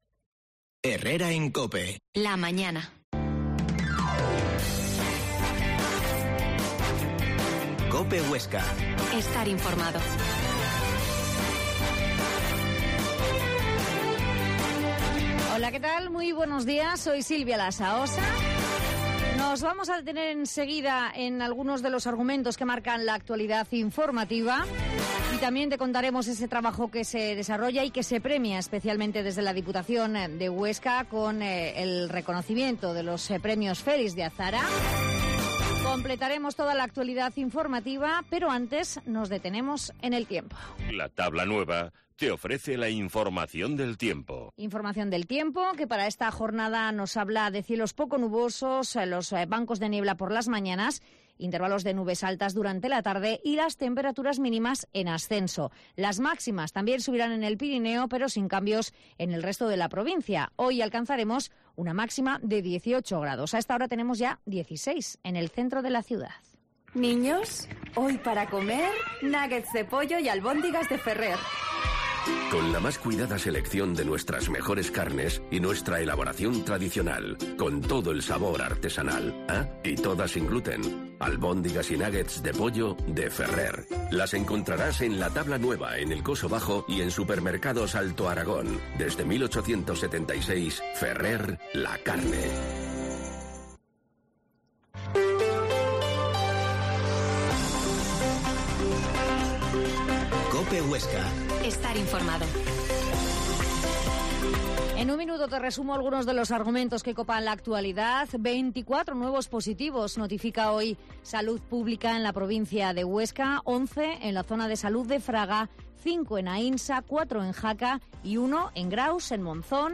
Herrera en Cope Huesca 12,50h. Entrevista del pte de Desarrollo de la DPH, Roque Vicente